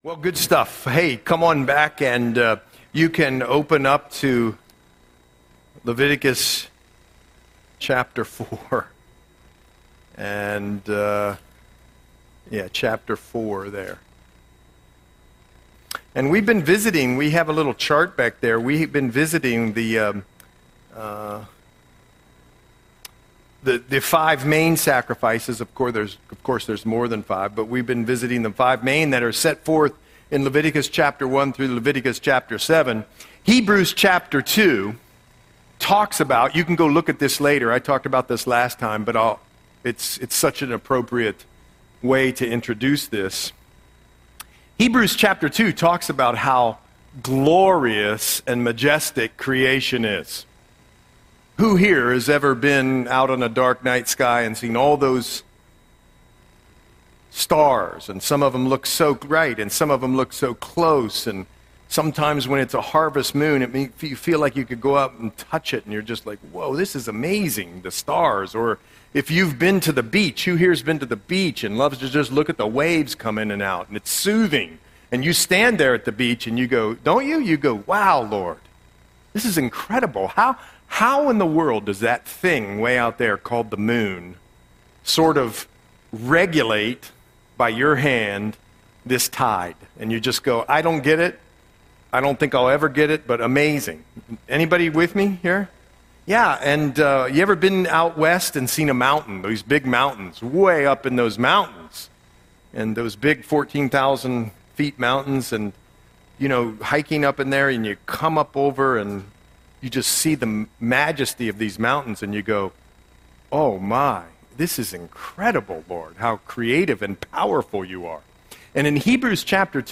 Audio Sermon - September 17, 2025